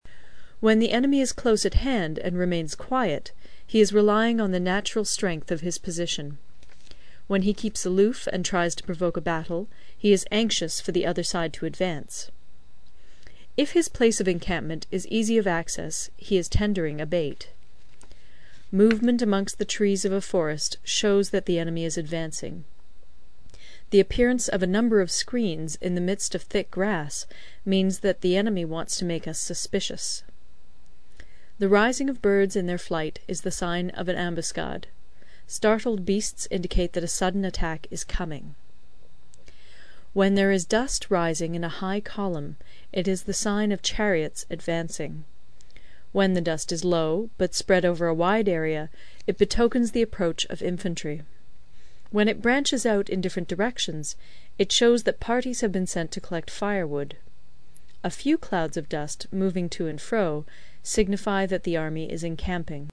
有声读物《孙子兵法》第53期:第九章 行军(4) 听力文件下载—在线英语听力室